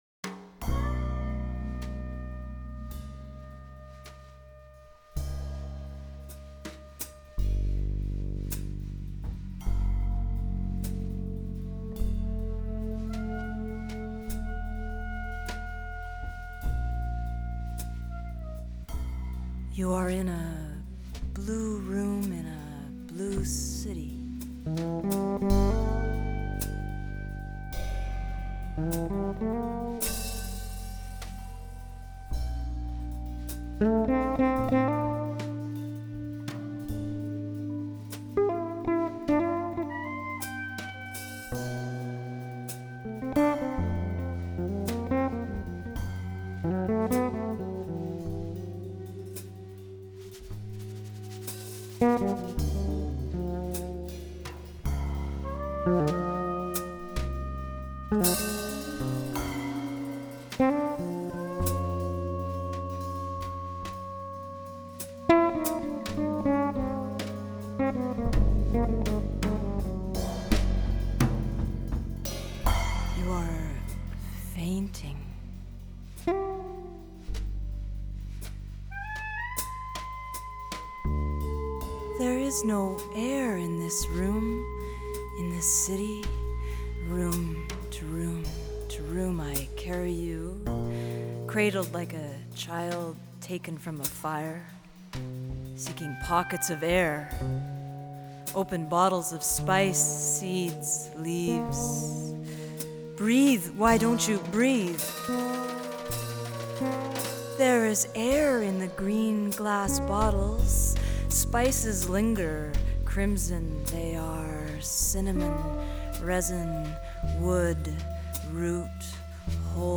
Recorded at CBC Studio 11 in Winnipeg